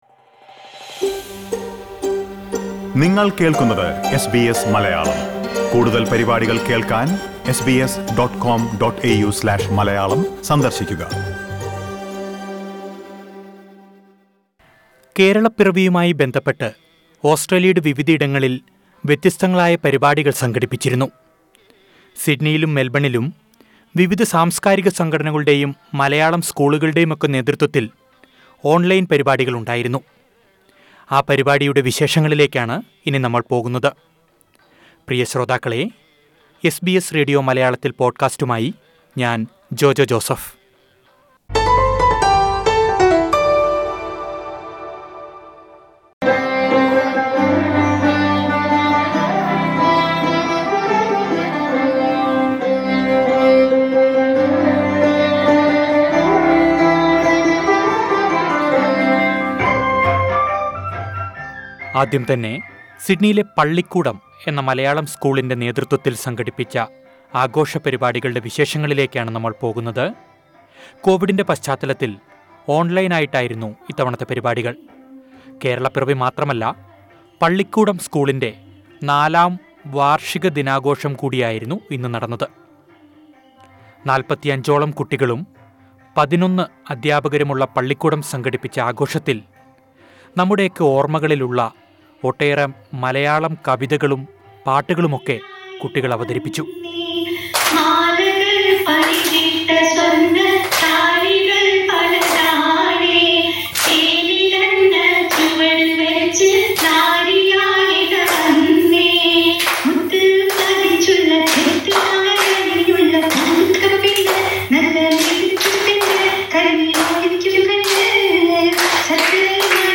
കേരളപ്പിറവി ആഘോഷമാക്കി 'പള്ളിക്കൂടം' കുട്ടികൾ; നാടൻ പാട്ടും, നാടകവുമായി വിപഞ്ചിക ഗ്രന്ഥശാല